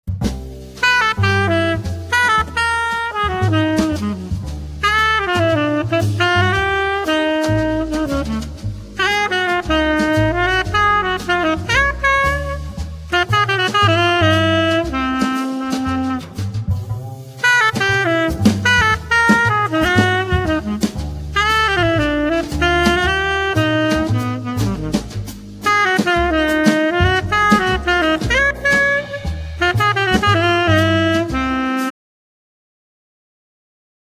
tenor saxophone
guitar
drums